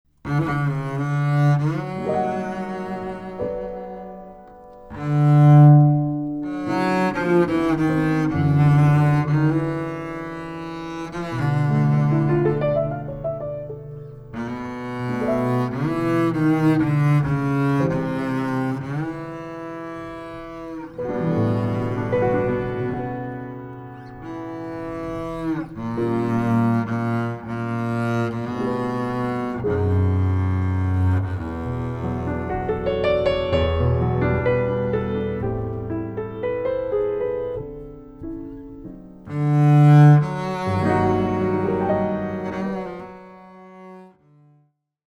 Recorded on July 13.2025 at Studio Happiness